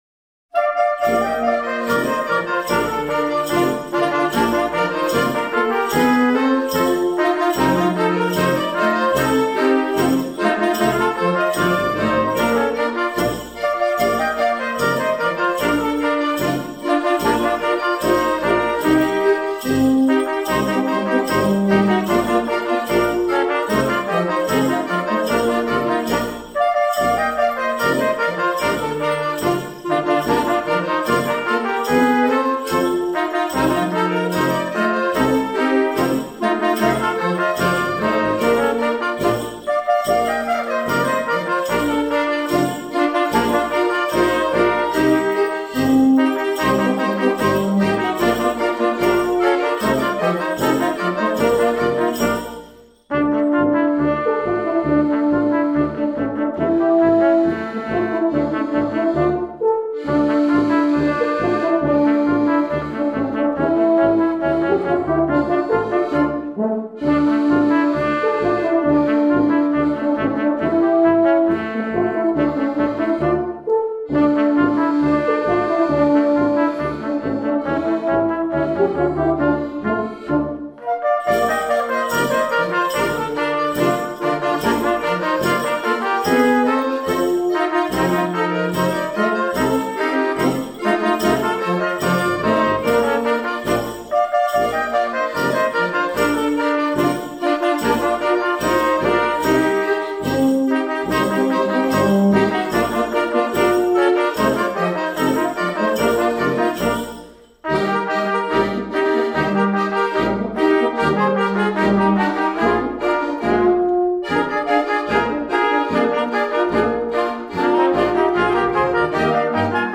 La Brante, Musique villageoise de Bernex: Noces à St. Gervais (Polka)